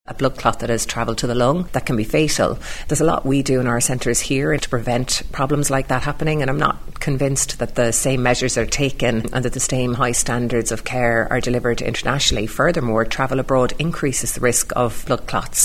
She says that bariatric surgery is usually straight-forward but there are always risks: